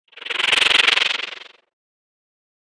جلوه های صوتی
دانلود صدای بیابان 2 از ساعد نیوز با لینک مستقیم و کیفیت بالا
برچسب: دانلود آهنگ های افکت صوتی طبیعت و محیط دانلود آلبوم صدای بیابان از افکت صوتی طبیعت و محیط